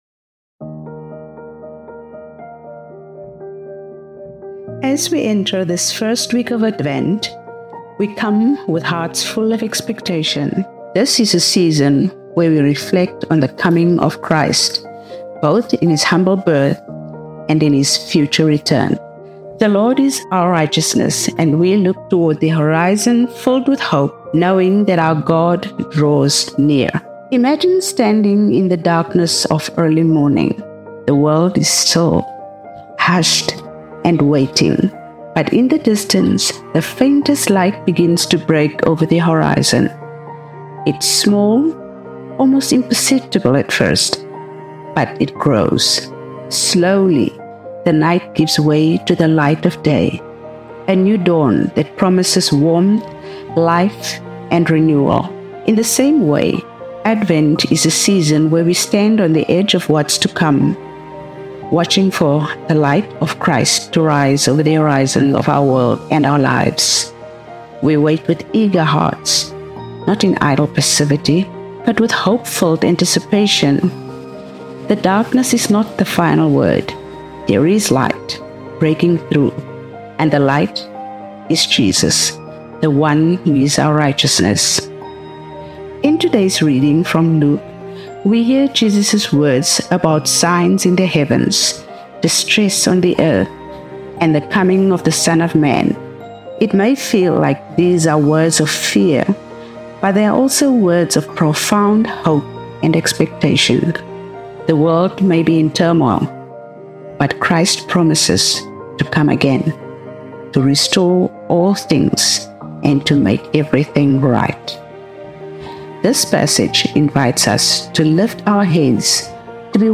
Sermon for December 1, 2024 – First Sunday of Advent